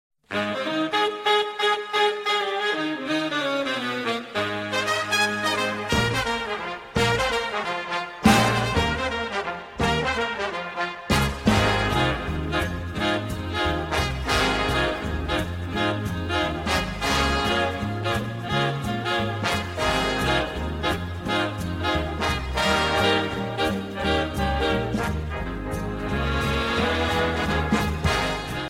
Jazz Ringtones
8 years ago Swing Jazz